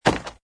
woodstone3.mp3